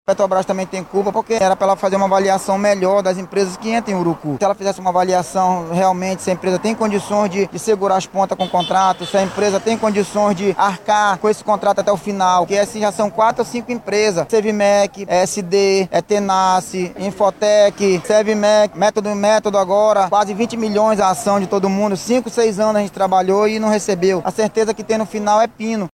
Um colaborador da empresa terceirizada, não identificado, relata as dificuldades enfrentadas pelos mais de 300 funcionários.
SONORA-2-PROTESTO-TRABALHADORES-URUCU-2.mp3